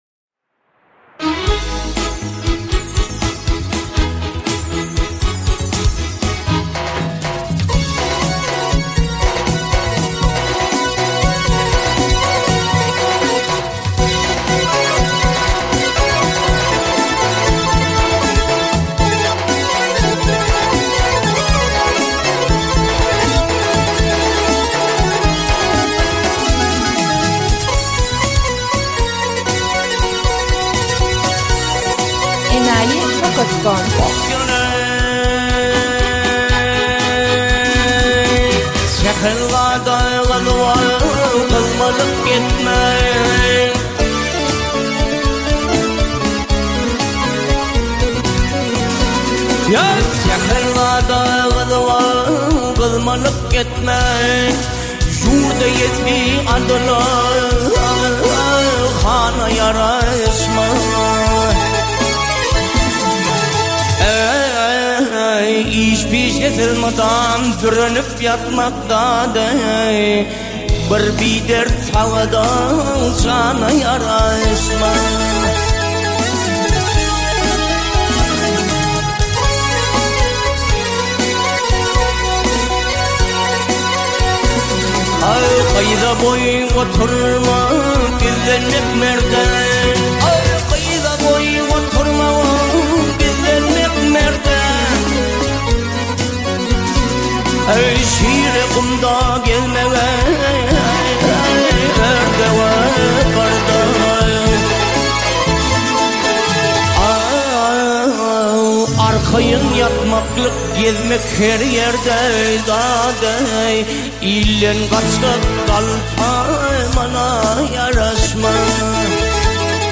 Туркменские песни